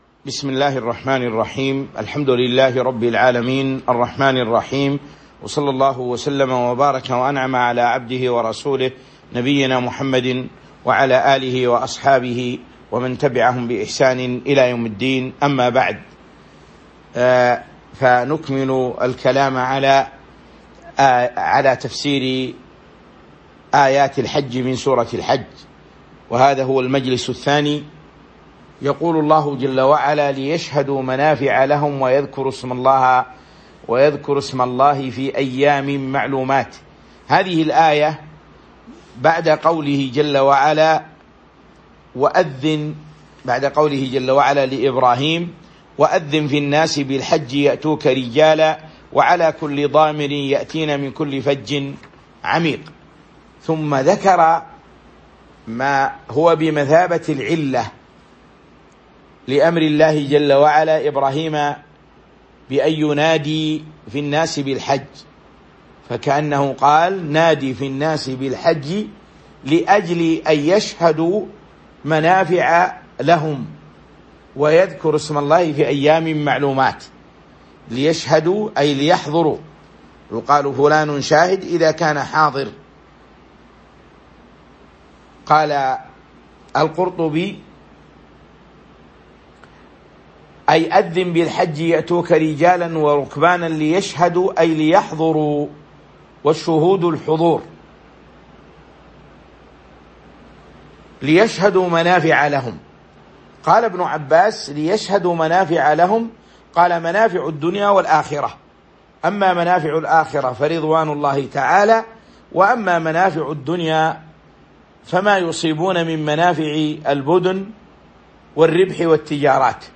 تاريخ النشر ٢٢ ذو القعدة ١٤٤٢ هـ المكان: المسجد النبوي الشيخ